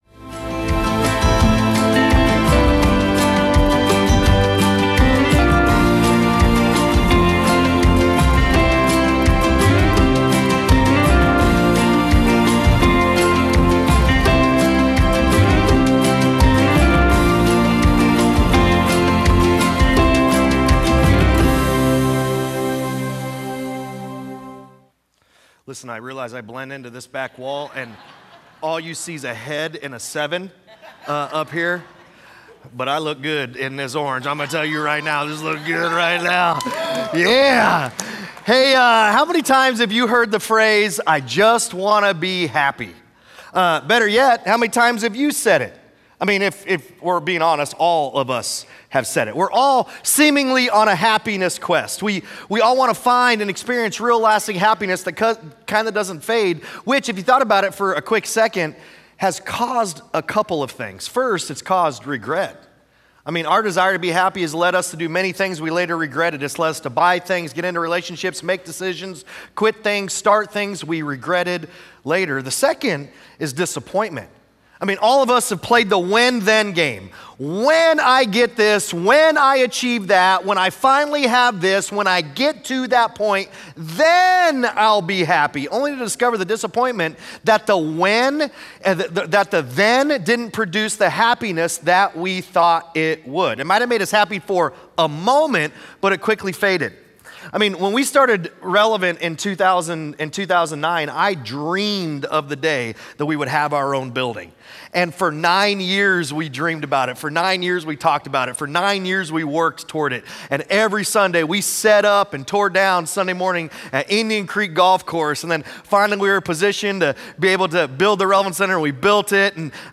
Sunday Sermons FruitFULL, Week 2: "Joy" Feb 08 2026 | 00:36:55 1x 00:00 / 00:36:55 Subscribe Share Apple Podcasts Spotify Overcast RSS Feed Share Link Embed